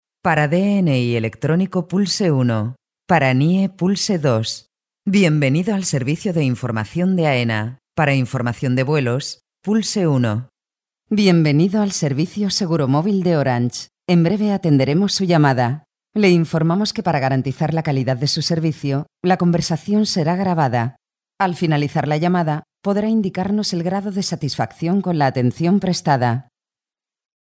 kastilisch
Sprechprobe: Sonstiges (Muttersprache):